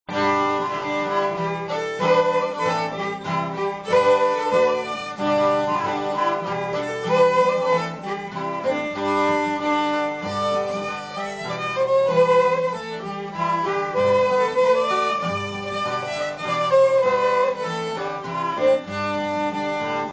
Fiddles
Guitar